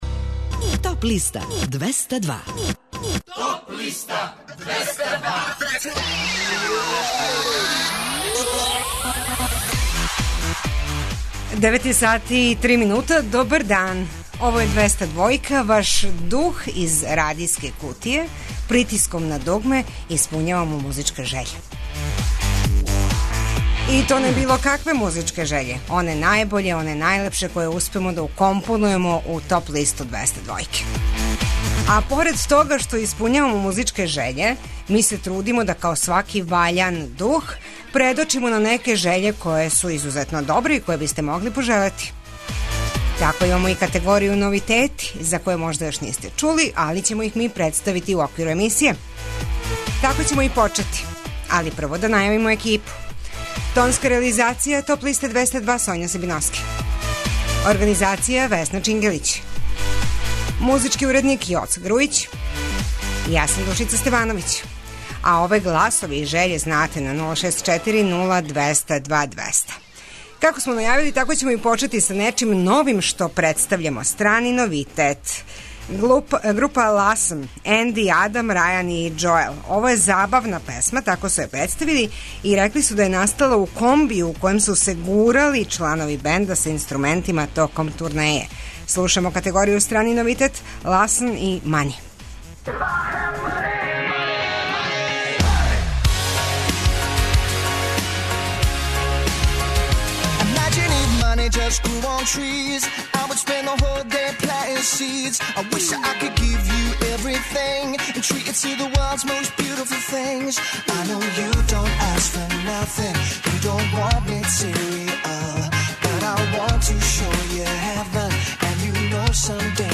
Најавићемо актуелне концерте, подсетићемо се шта се битно десило у историји музике у периоду од 28. марта до 1. априла. Емитоваћемо песме са подлиста лектире, обрада, домаћег и страног рока, филмске и инструменталне музике, попа, етно музике, блуза и џеза, као и класичне музике.